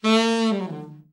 ALT FALL  18.wav